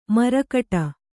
♪ marakaṭa